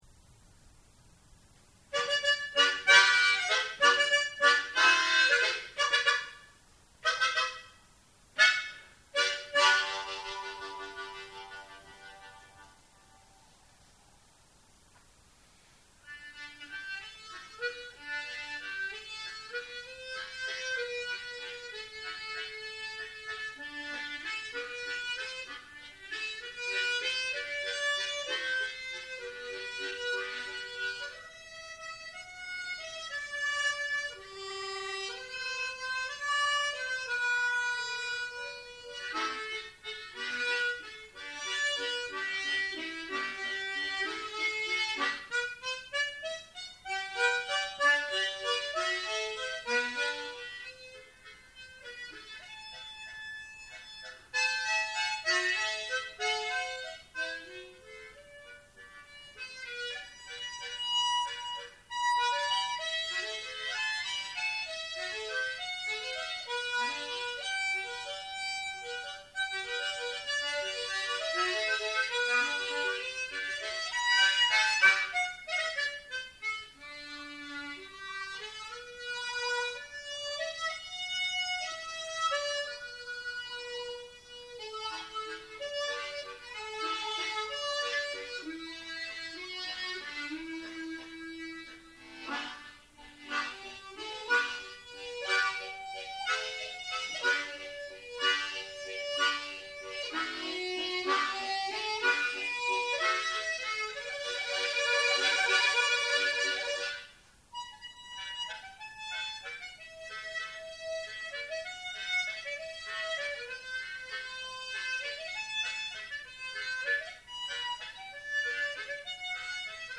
口琴-風流寡婦.mp3